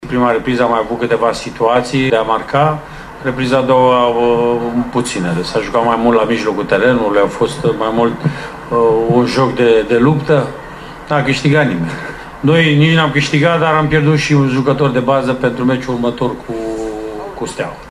În schimb, tehnicianul Viitorului, Mircea Rednic, s-a declarat dezamăgit nu doar că n-a obținut mai mult ci a și pierdut un jucător important în perspectiva următorului meci: